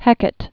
(hĕkət, -ə-tē)